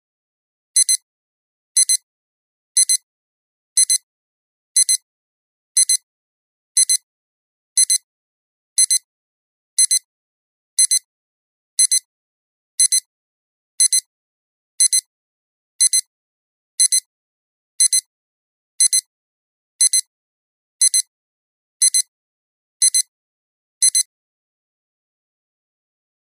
Watch, Digital; Digital Watch Alarm. Two Takes.